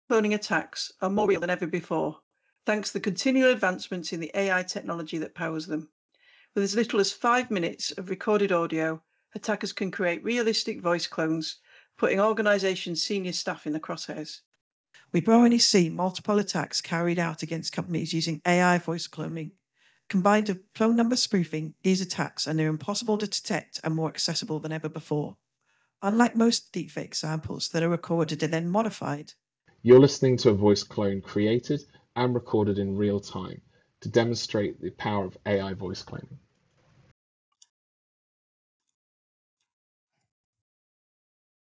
It demonstrates that the real-time deepfake is both convincing and can be activated without discernible latency.
The quality of the input audio used in the demonstration is also rather poor, yet the output still sounds convincing.